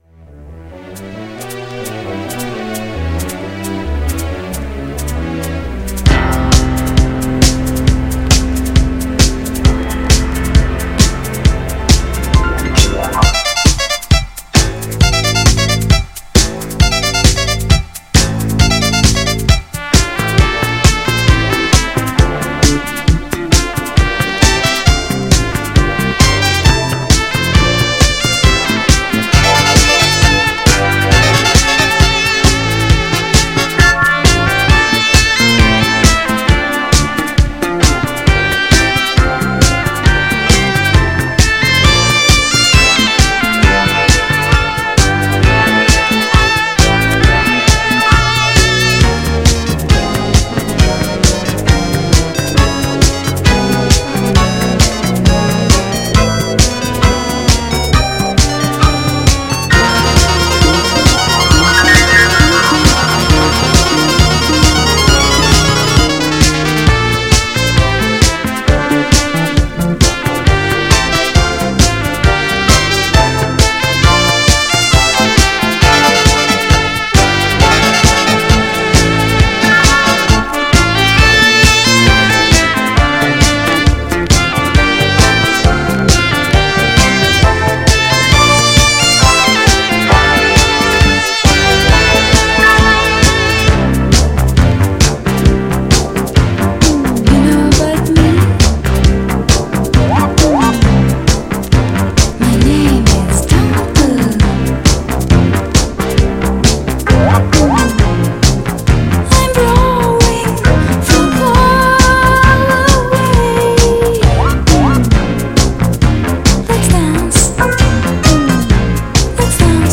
GENRE Dance Classic
BPM 116〜120BPM